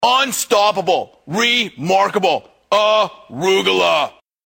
Jim Rome Impression - Unstoppable...Arugula